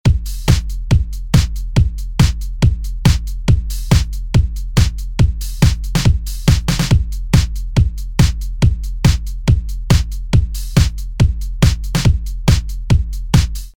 Note: In these examples, Version A is with no effect, Version B is with EQ and compression added, and Version C is with the preamp saturating, as well as EQ and even more compression.